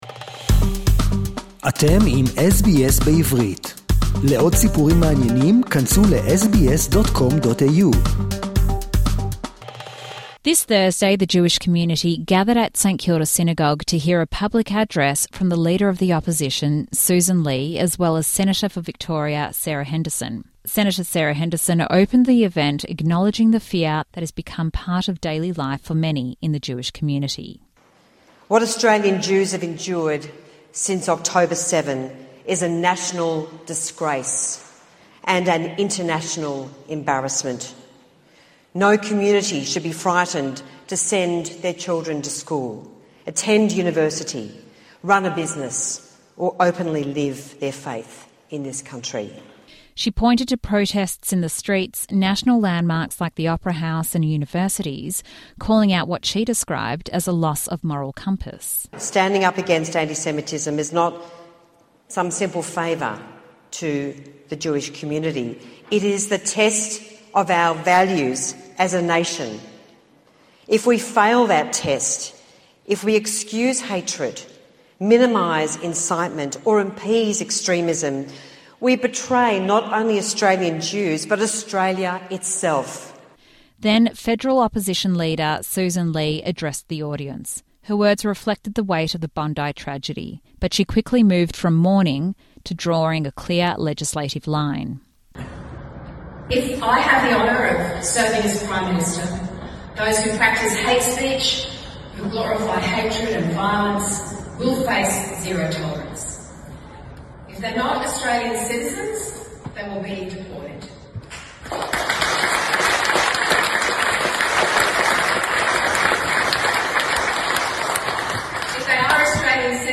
Leader of the Opposition, Sussan Ley and Senator Sarah Henderson make a joint public address
Coalition leader, Sussan Ley and Senator for Victoria, Sarah Henderson give a joint public address